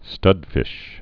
(stŭdfĭsh)